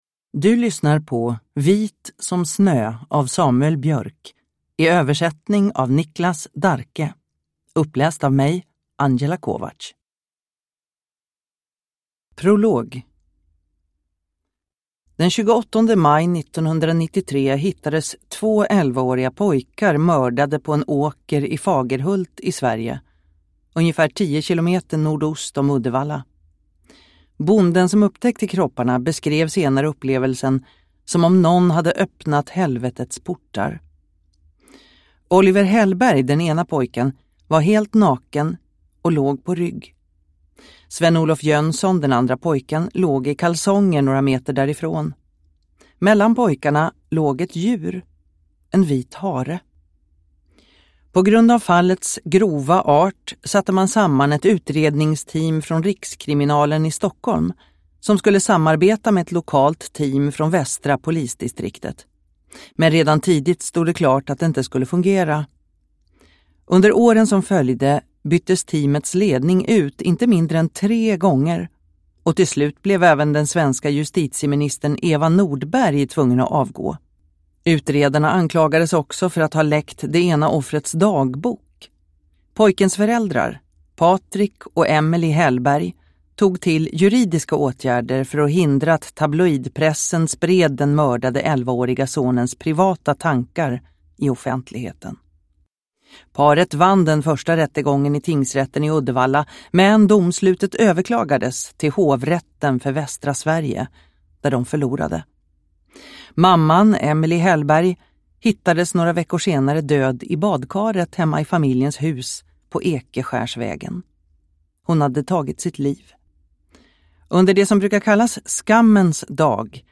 Vit som snö – Ljudbok